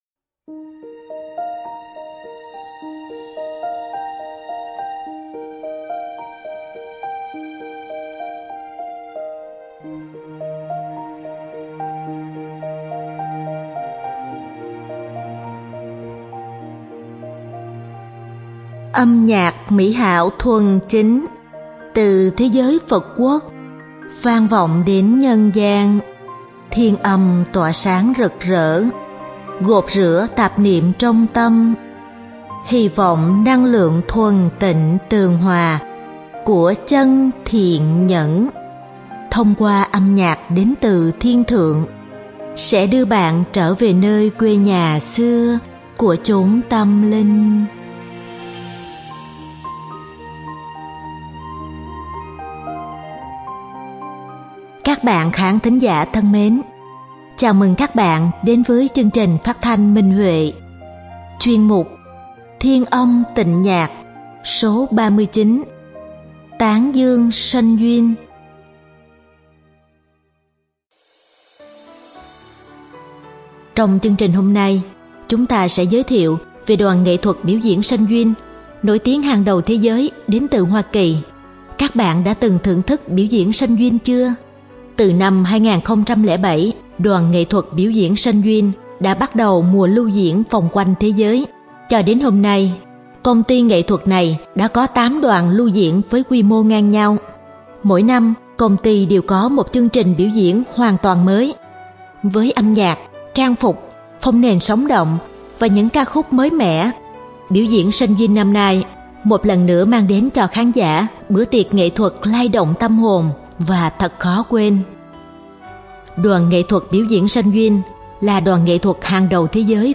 Âm nhạc mỹ hảo thuần chính, từ thế giới Phật quốc vang vọng đến nhân gian, thiên âm tỏa sáng rực rỡ, gột rửa tạp niệm trong tâm, hy vọng năng lượng thuần tịnh